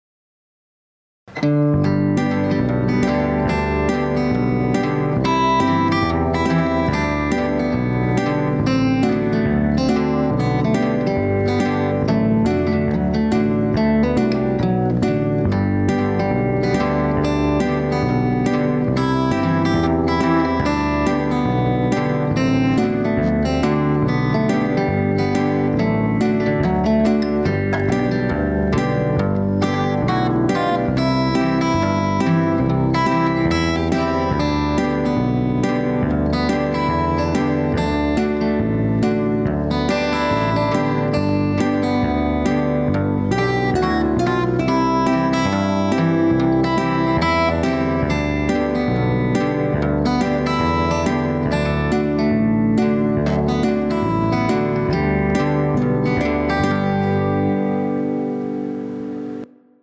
Here is a backing track I recorded for Redwing, at a mid tempo, may be easier for some players than the fast version.
I used 2 acoustic guitars and a bass guitar in the key of G.